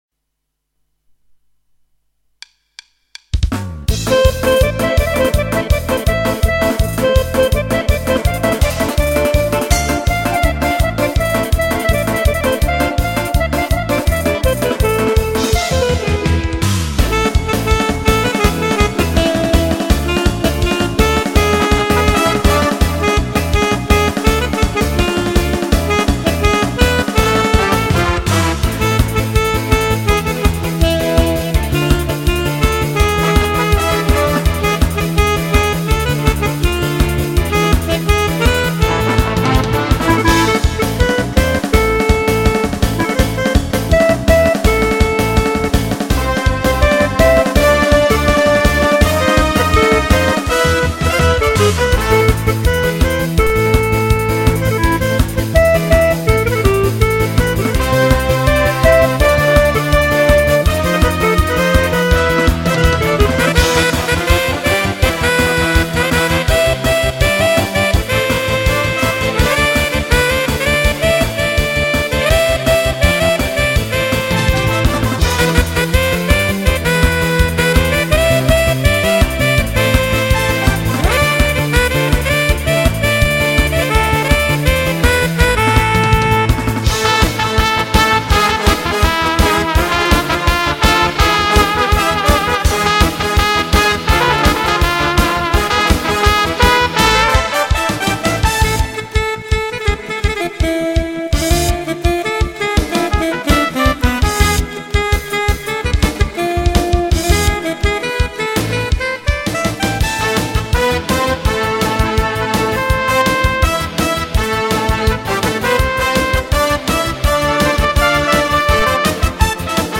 באורגן. כמה הקלטות אחת על גבי השנייה.
יצא טוב :) נשמע כמו תזמורת